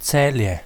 Pronunciation of Celje
Sl-Celje.oga.mp3